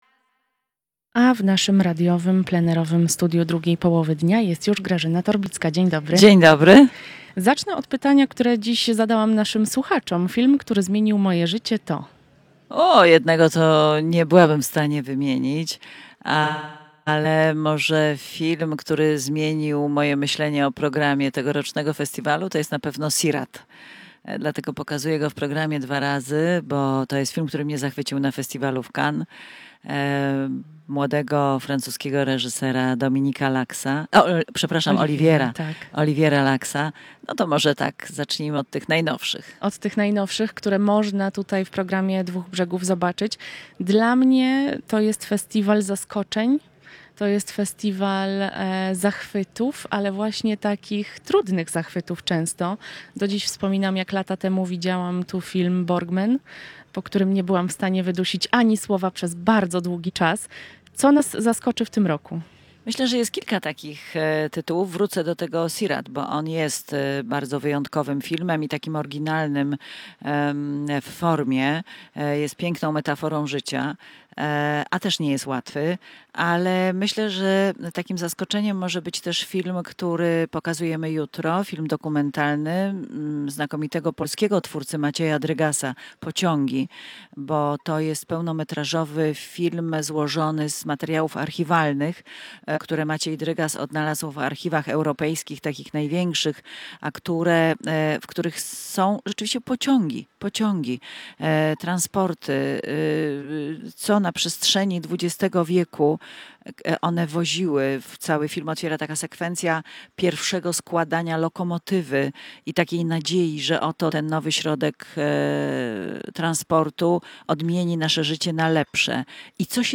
Grażyna Torbicka, dyrektorka artystyczna BNP Paribas Festiwalu Filmu i Sztuki Dwa Brzegi odwiedziła plenerowe studio Radia Lublin.